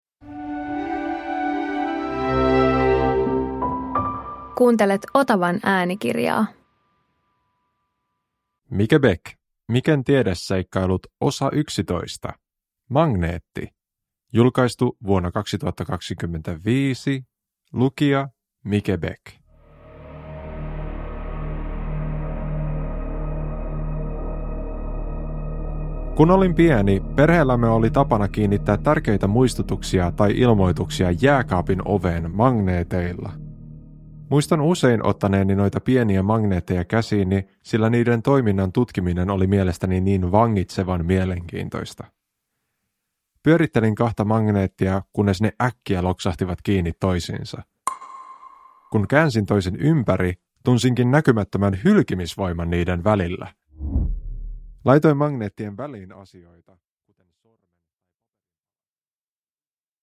Uppläsare: Mike Bäck